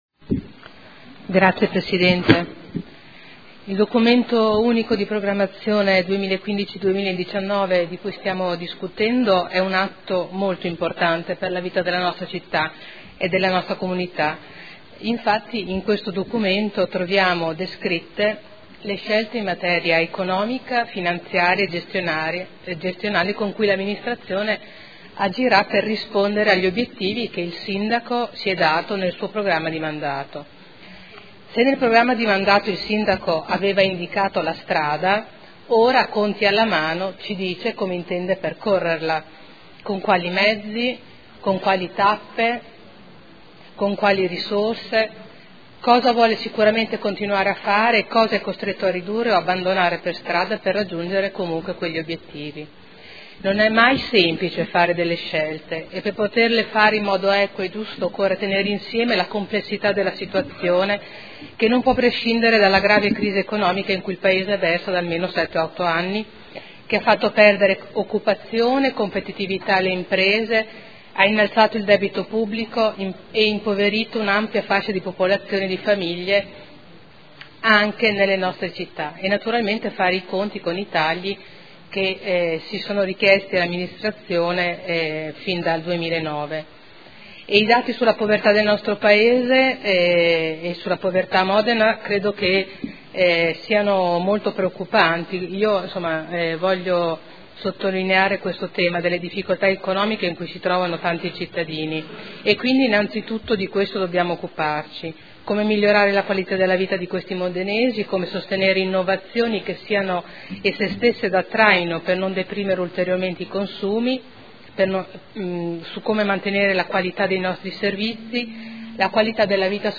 Seduta del 29/01/2015. Documento Unico di Programmazione 2015/2019 – Sezione strategica.